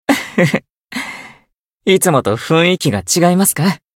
觉醒语音 和平时给人的感觉不太一样吗 いつもと雰囲気が違いますか 媒体文件:missionchara_voice_657.mp3